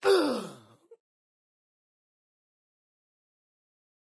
Creature_Sounds-Humanoid_Death_1.ogg